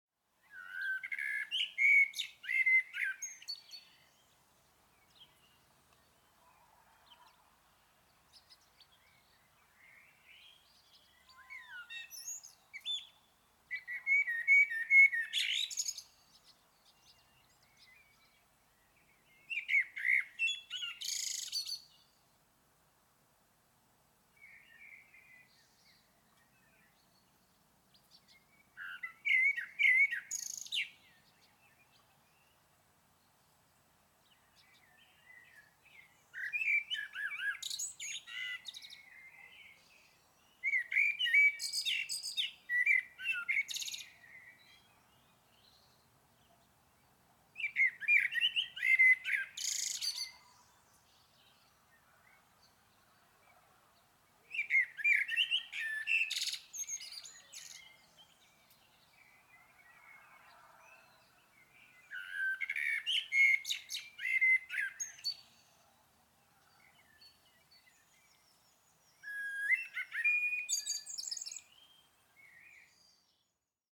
Happy birds
Category 🌿 Nature
bird birds nature outdoor singing sound effect free sound royalty free Nature